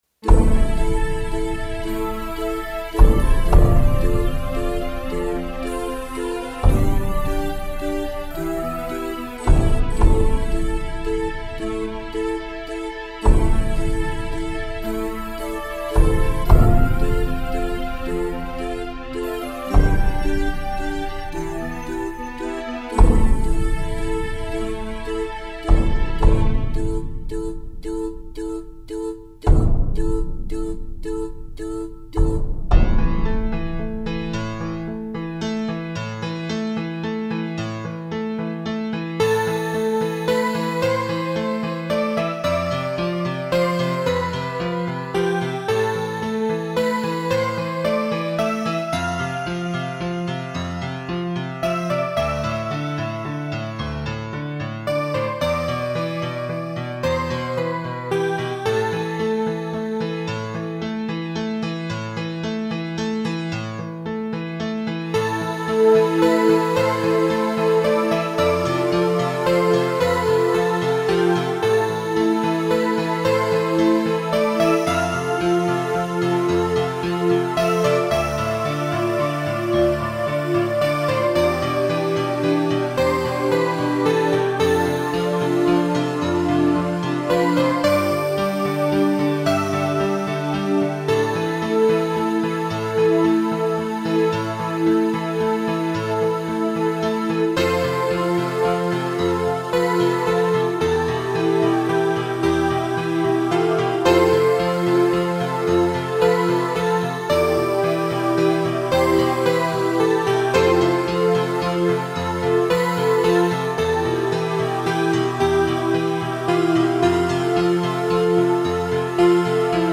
I brought every album this woman had made up to that point and dedicated myself to becoming a NewAge keyboard player.
Oh those were the days when me, my JV1080 and Cakewalk would crash Windows 98 time and time again.